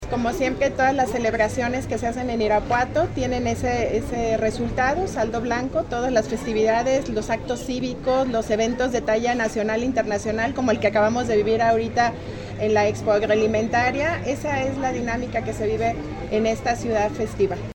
Lorena-Alfaro-Garcia-presidenta-muncipal.mp3